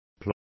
Complete with pronunciation of the translation of ploughs.